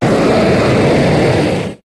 Cri de Groudon dans Pokémon HOME.